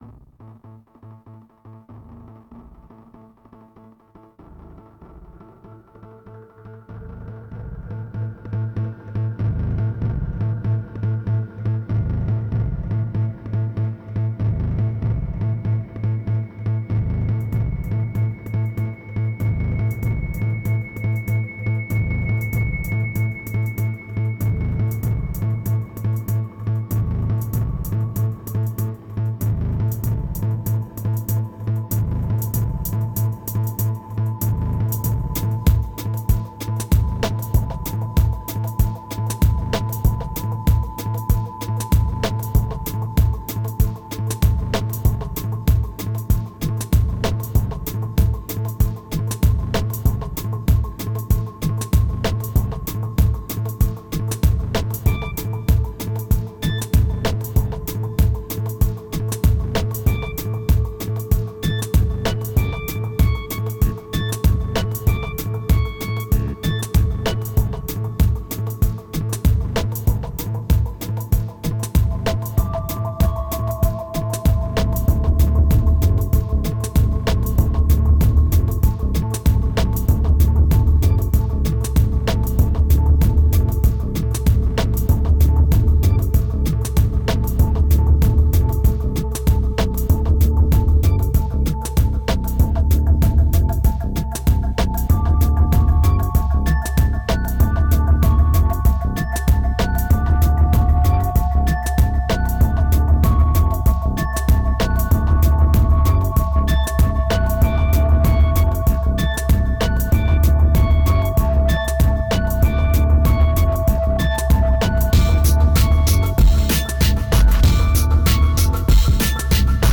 2043📈 - -6%🤔 - 96BPM🔊 - 2010-07-27📅 - -177🌟